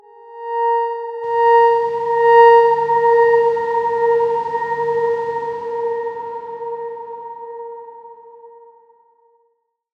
X_Darkswarm-A#4-pp.wav